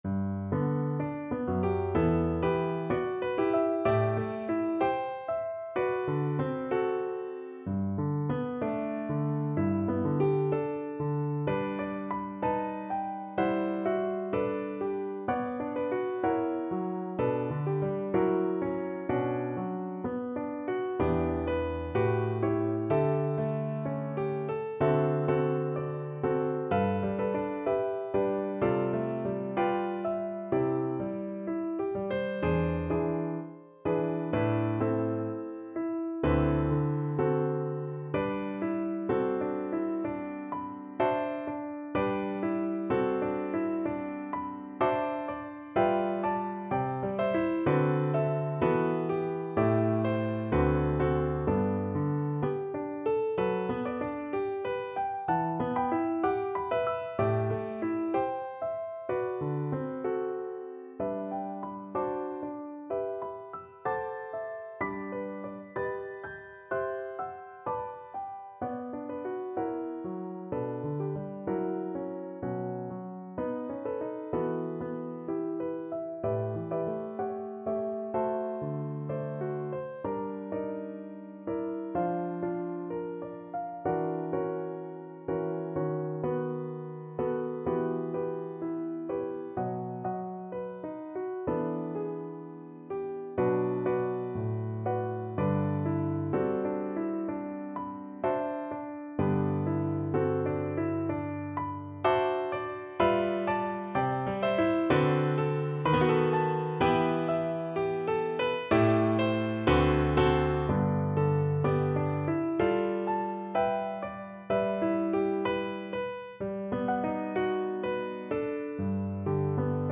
G major (Sounding Pitch) (View more G major Music for Viola )
Andante moderato poco con moto =63) (View more music marked Andante Moderato)
4/4 (View more 4/4 Music)
Classical (View more Classical Viola Music)